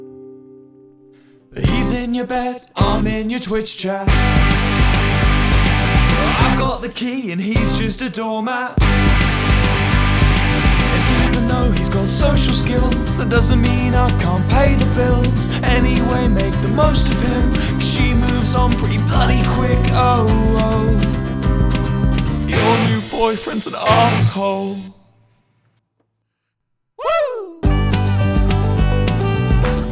• Comedy